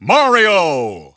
The announcer saying Mario's name in English and Japanese releases of Super Smash Bros. Brawl.
Mario_English_Announcer_SSBB.wav